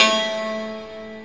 SI2 PIANO04L.wav